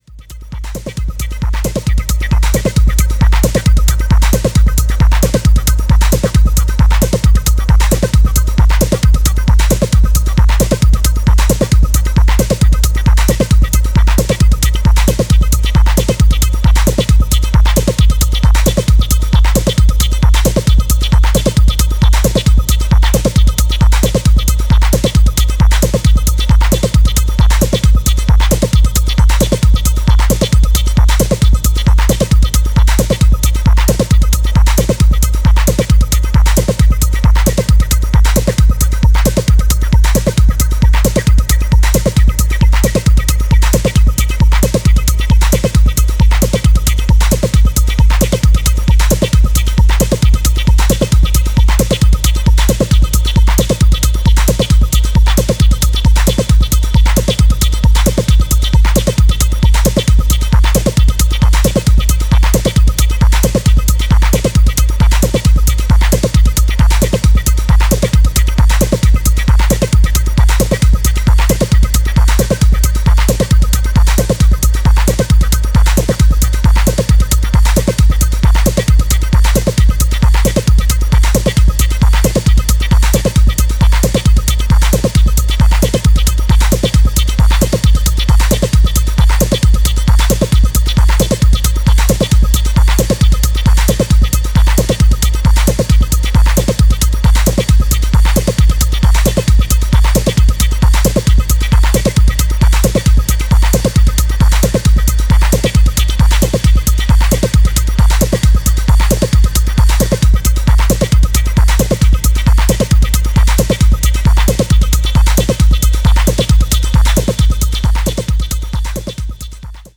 sharp rhythmic minimalism and diverse textures